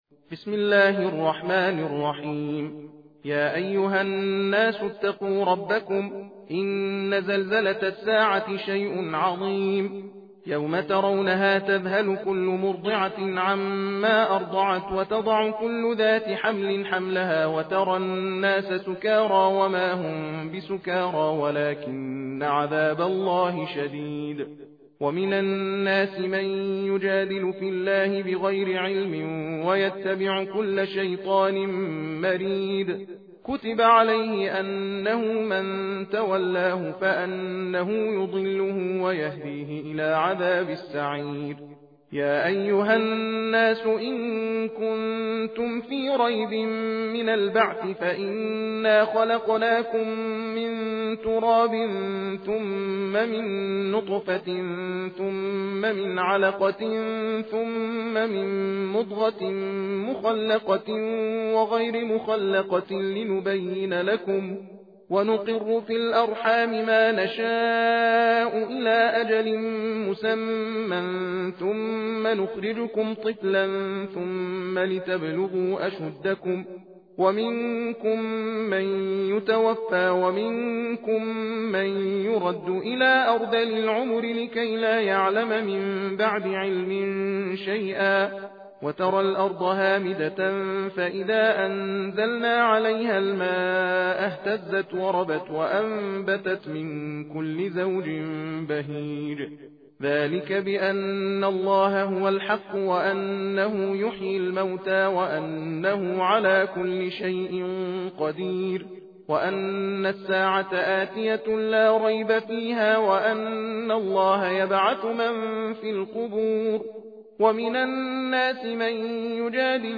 تحدیر (تندخوانی) سوره حج